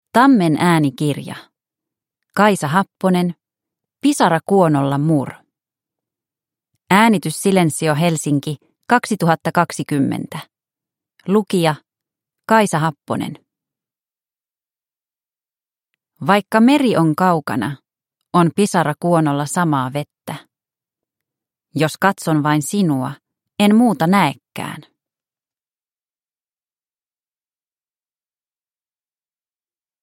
Pisara kuonolla, Mur – Ljudbok – Laddas ner